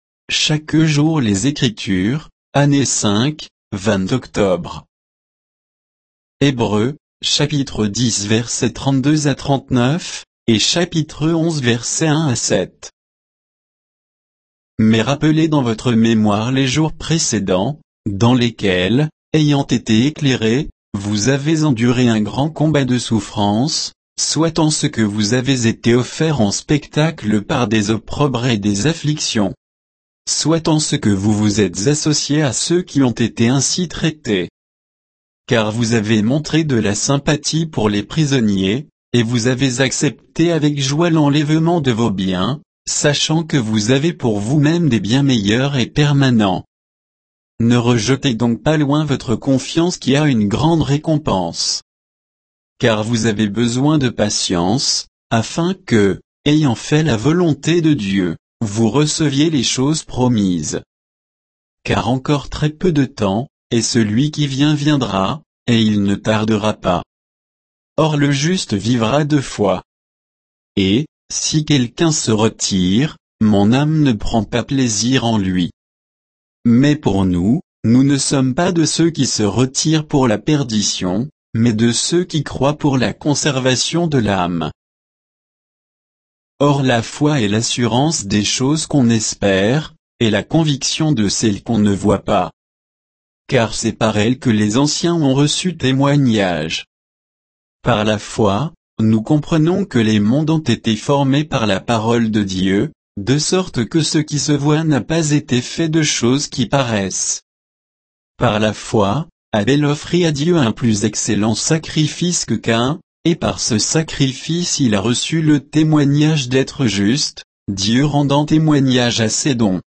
Méditation quoditienne de Chaque jour les Écritures sur Hébreux 10, 32 à 11, 7